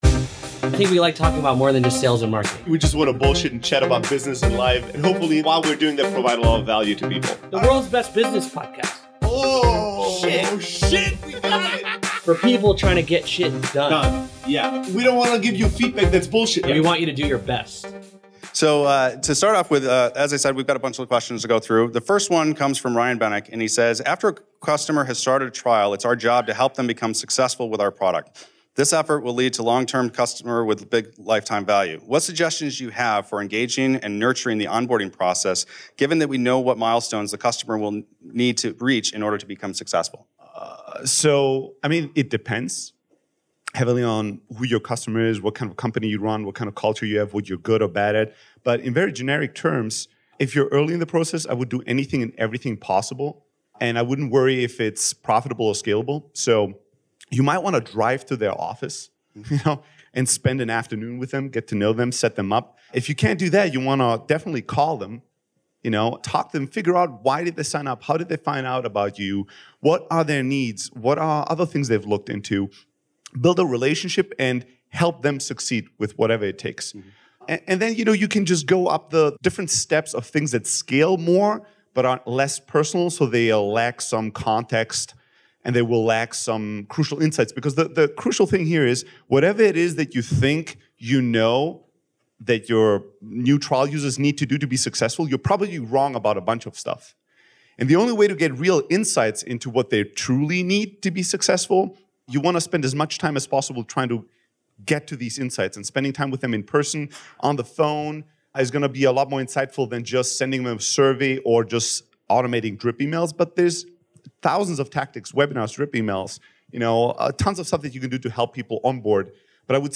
On today’s episode we share the Q & A session we did at MicroConf 2016.